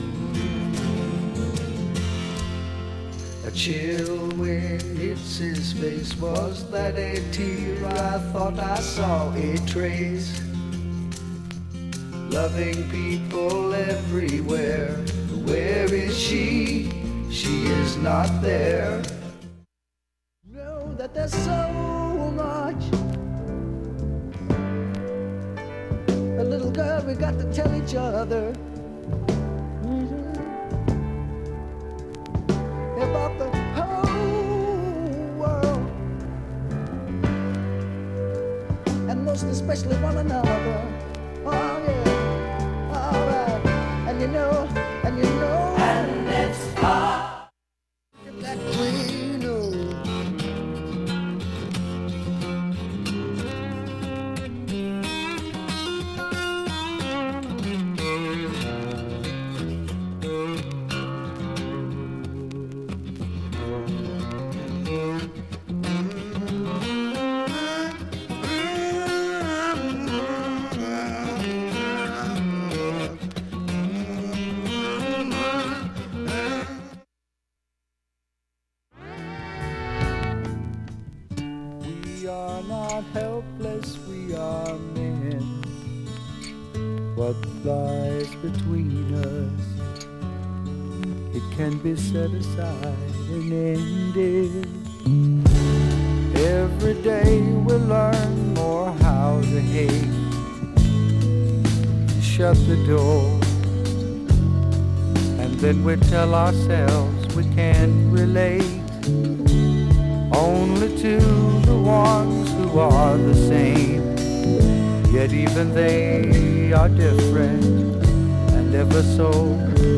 下記プツも聴き取れるか不安なレベルです。
A-2中盤にかすかなプツが7回出ます。